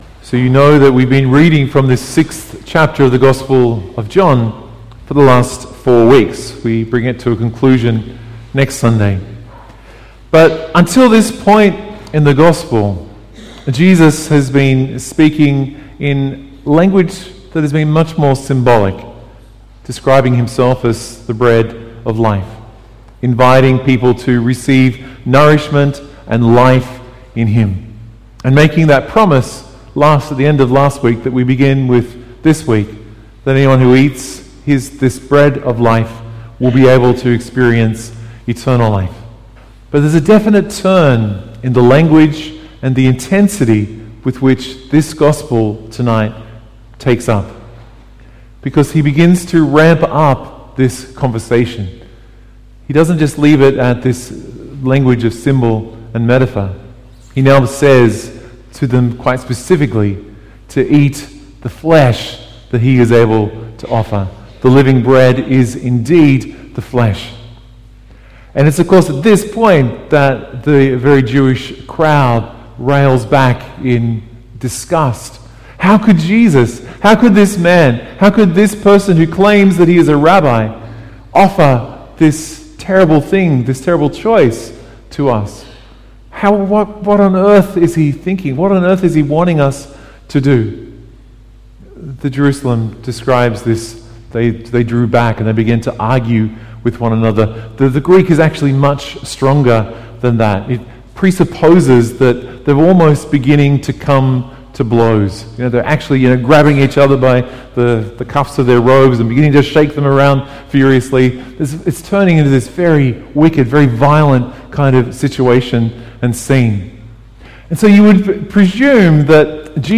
Download or Play MP3 MP3 Audio file (Saturday Vigil)
Liturgy of the Word